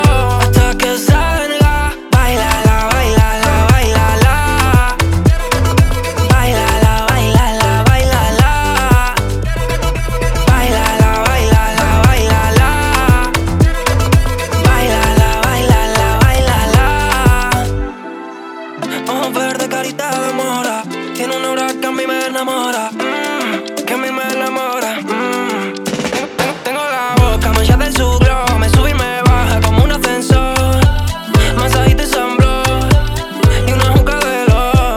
Жанр: Поп музыка / Электроника / Классика